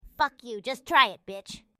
AI generated audio